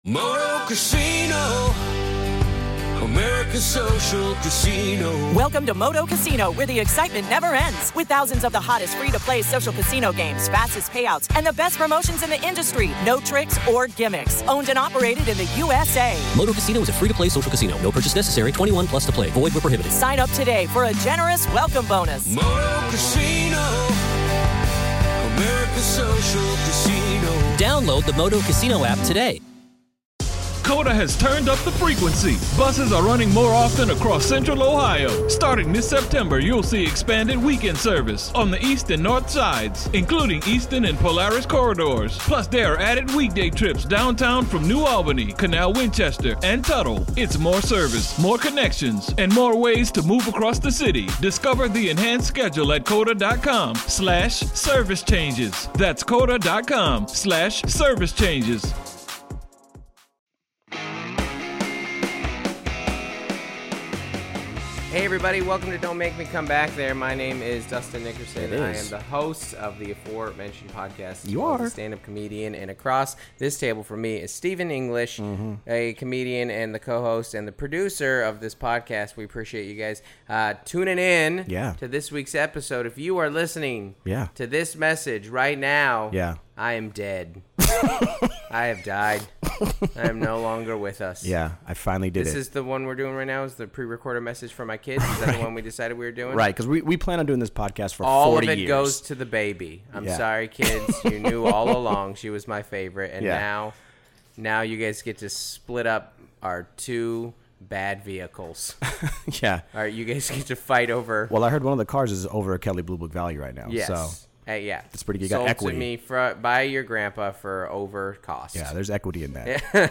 It's one of the best interviews we've done and possibly the best that anyone has EVER done.